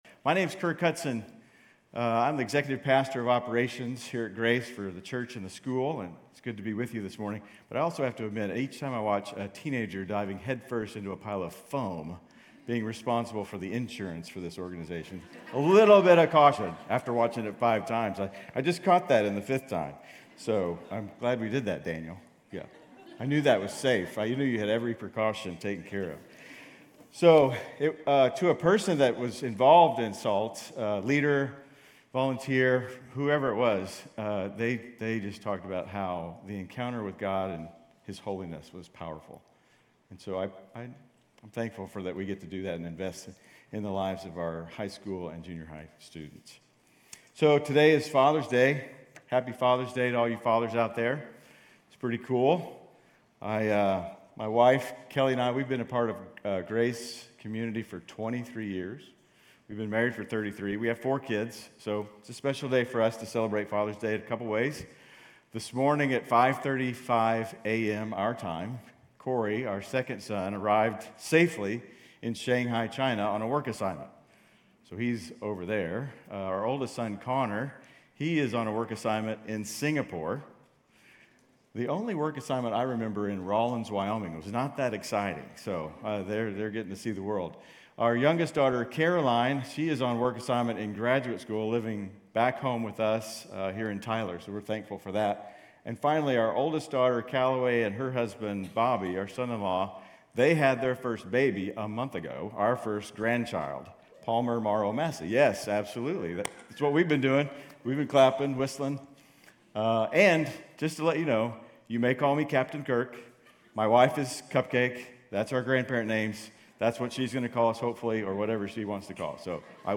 GCC-UB-June-18-Sermon.mp3